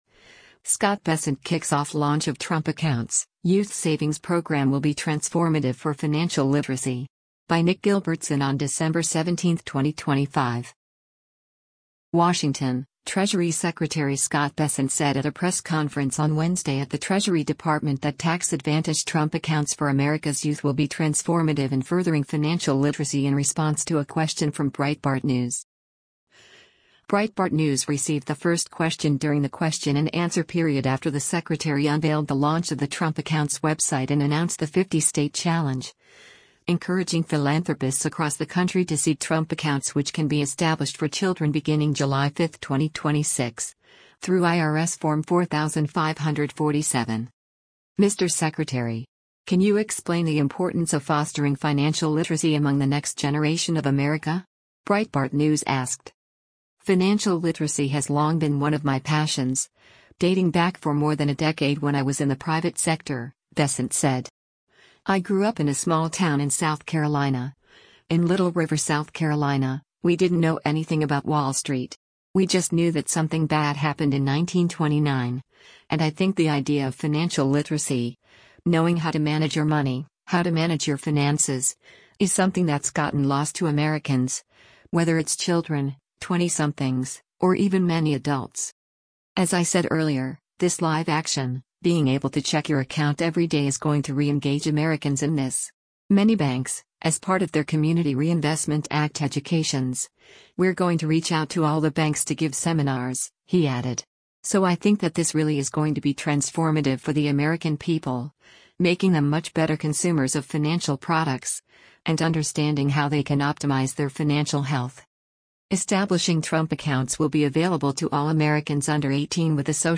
WASHINGTON—Treasury Secretary Scott Bessent said at a press conference on Wednesday at the Treasury Department that tax-advantaged Trump Accounts for America’s youth will be “transformative” in furthering financial literacy in response to a question from Breitbart News.